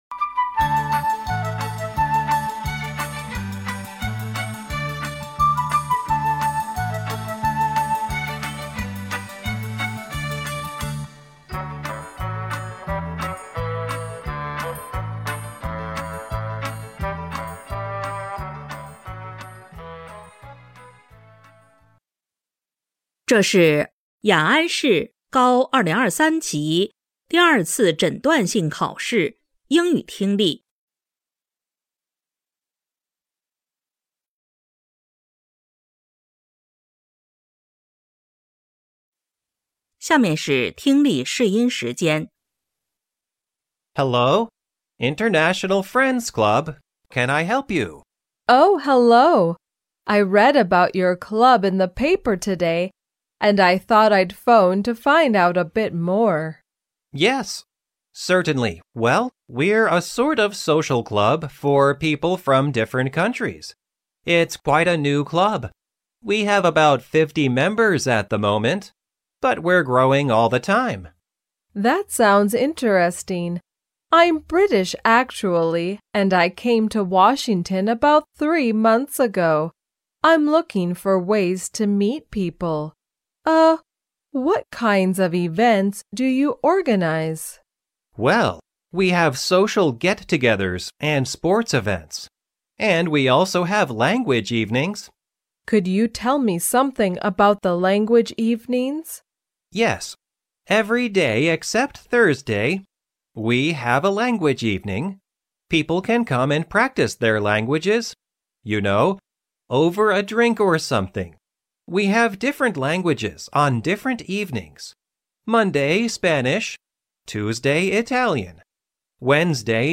2026届雅安二诊英语听力.mp3